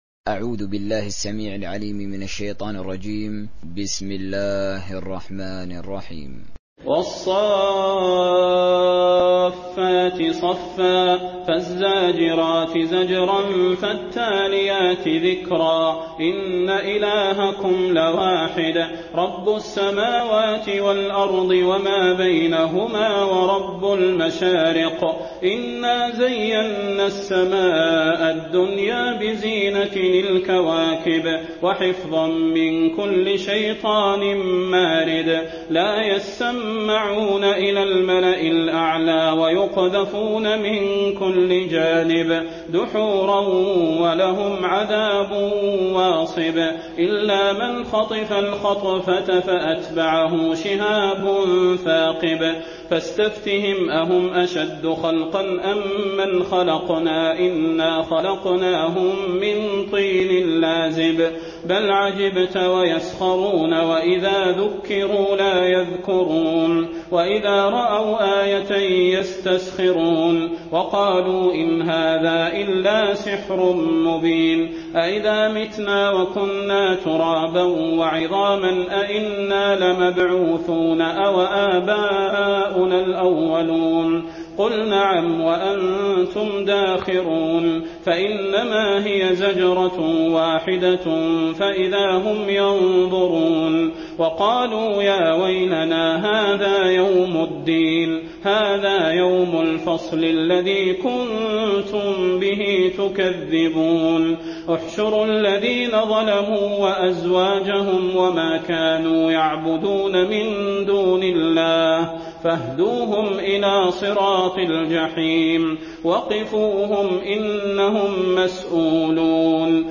تراويح